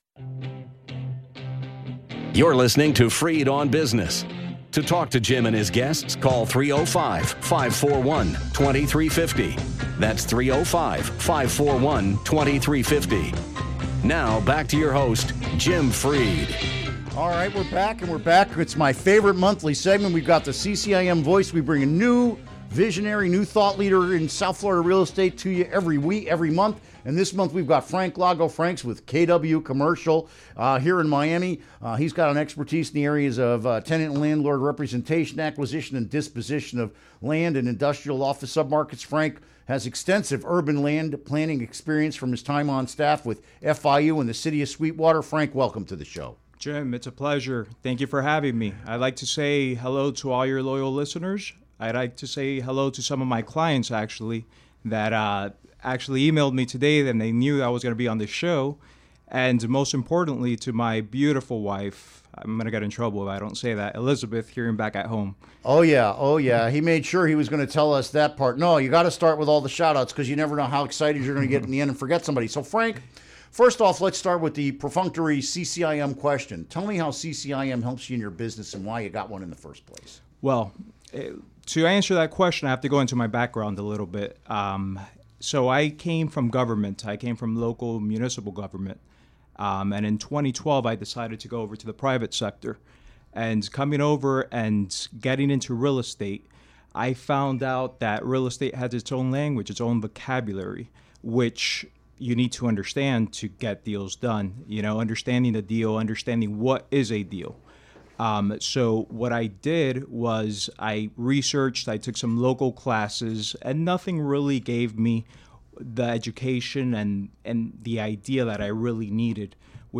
Interview Segment Episode 374: 06-23-16 Download Now!